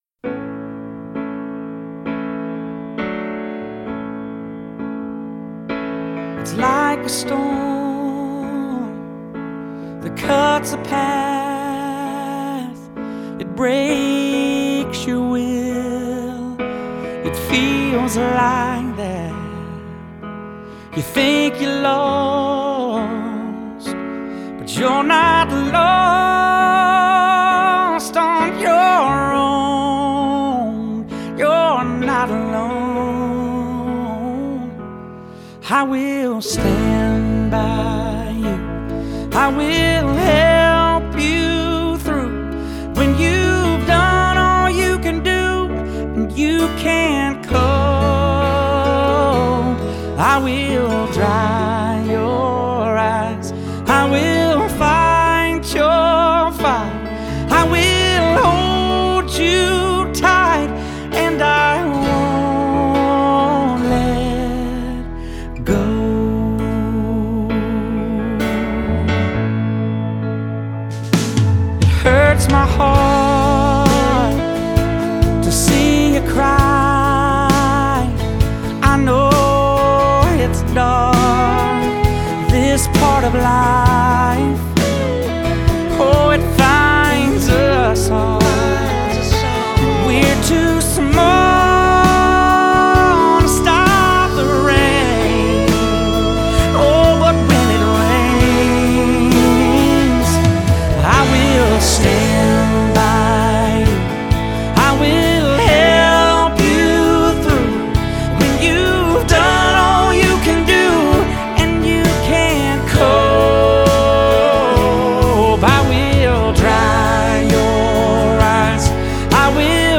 These are some of our favorite slow dance songs.